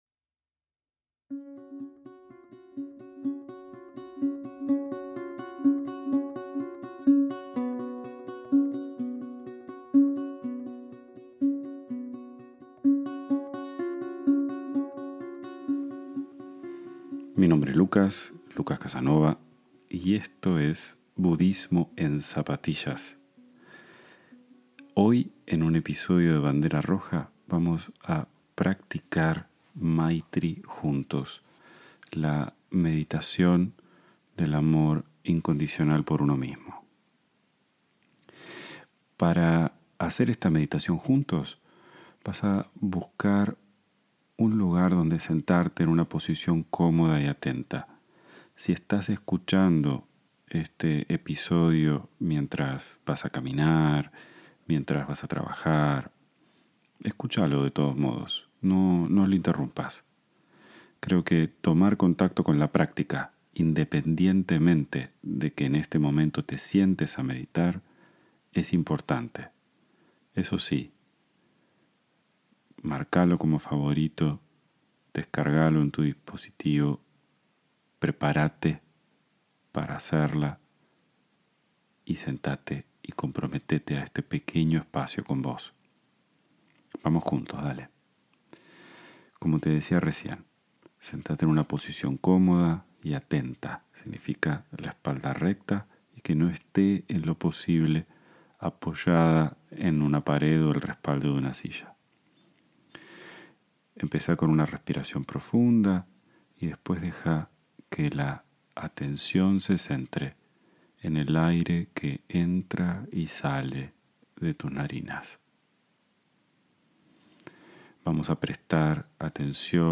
En este episodio vamos a practicar juntos "Maitri", la meditación del amor incondicional a uno mismo.